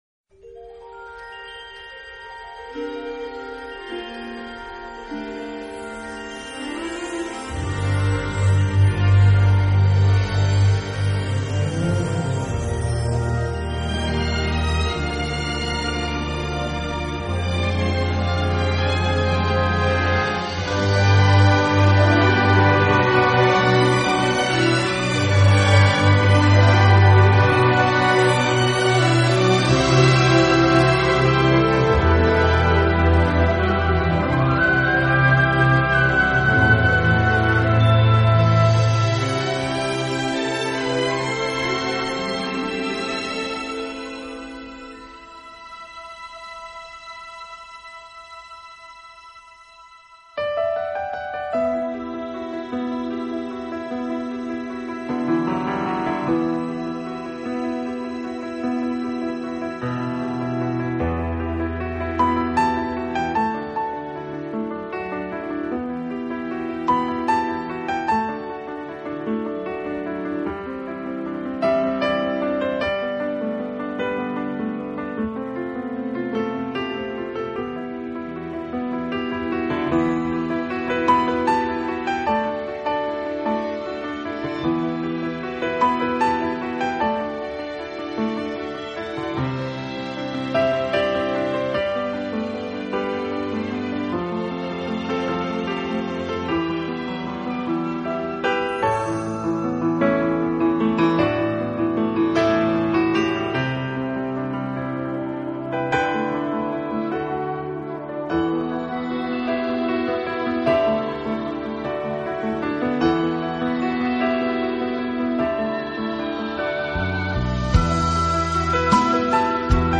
他擅长以优美的旋律描述自然美景和浪漫心境，钢琴音色温暖，演奏有如行